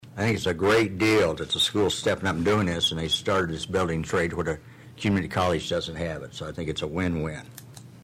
During the August 16 City Council meeting, Councilman Pat McCurdy commended the Atlantic School District for Offering this program for the students.